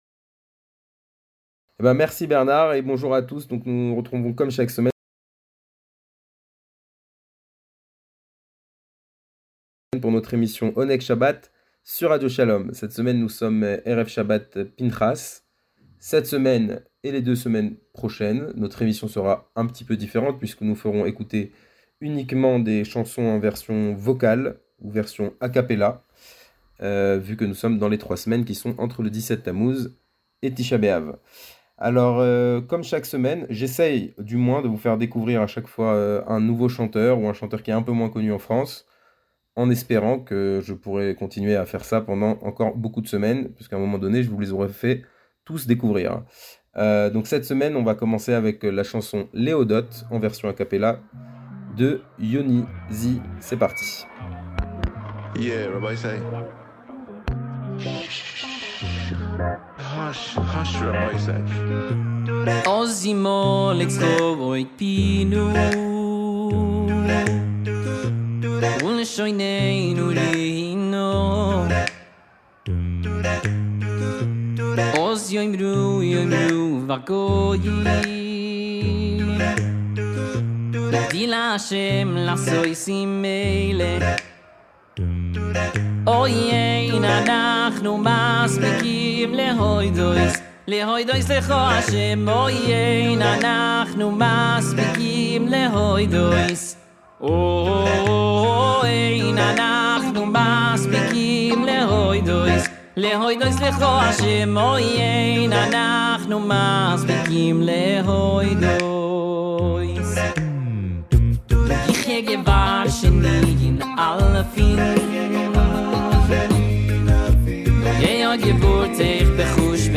Le meilleur de la musique juive, tous les vendredis après-midi juste après Kabalat shabat, aux alentours de 17h20 !